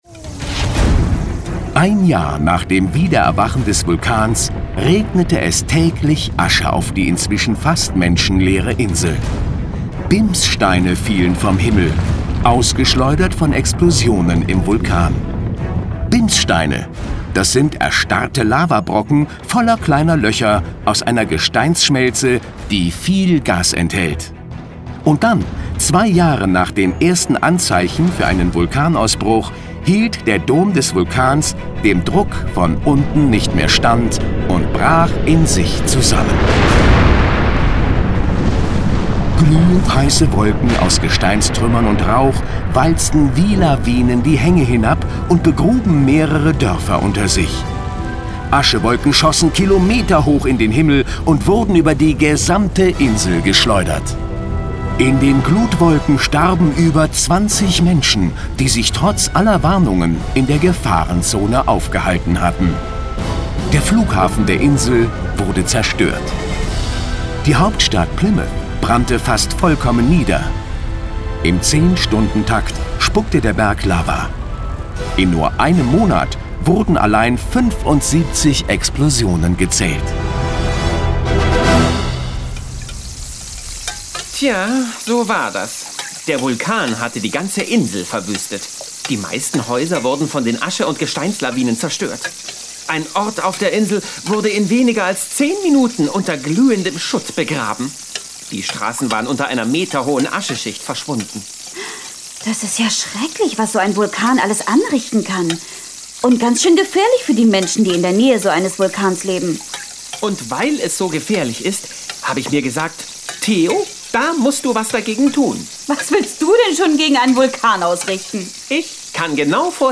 Hörbuch